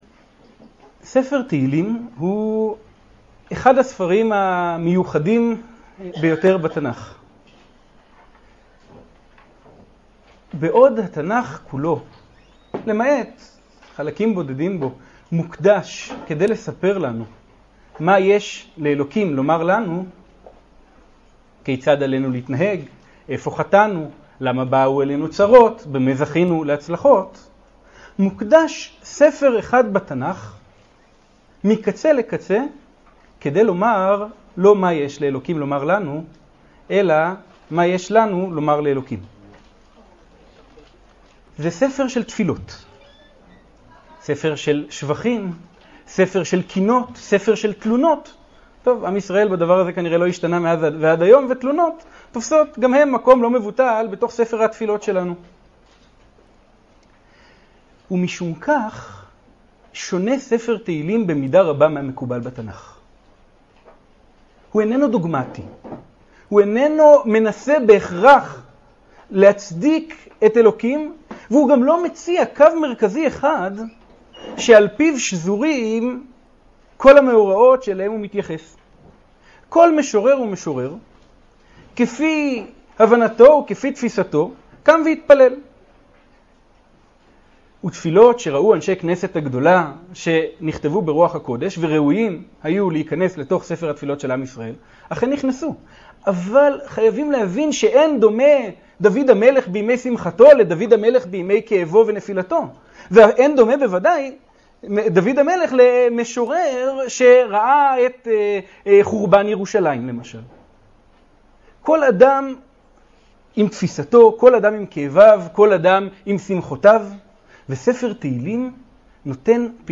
השיעור באדיבות אתר התנ"ך וניתן במסגרת ימי העיון בתנ"ך של המכללה האקדמית הרצוג תשס"ט